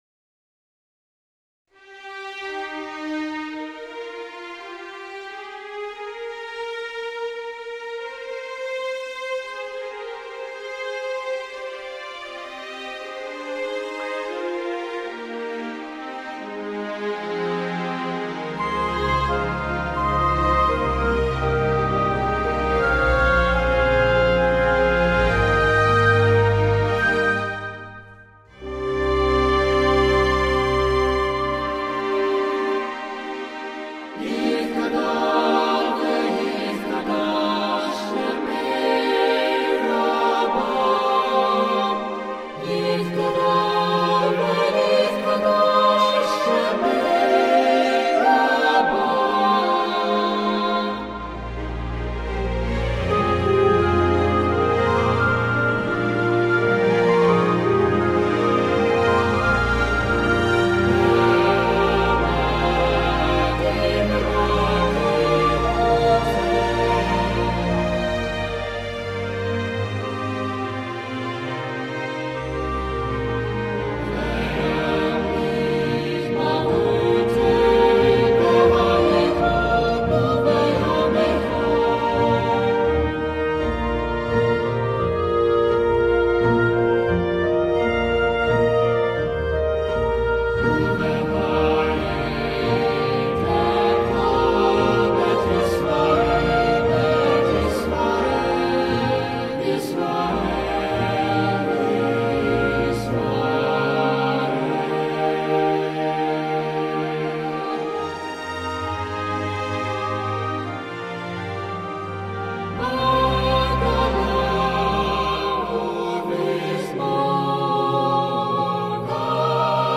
Kaddish of Hope for Chorus and Orchestra
It is included in all three daily prayer services. This setting is called “Kaddish of Hope” as its uplifting setting is a celebration of good things to come. Kaddish of Hope is set for SATB chorus and chamber orchestra Here is the English Translation from the Aramaic Glorified and sanctified be God’s great name throughout the world which He has created according to His will.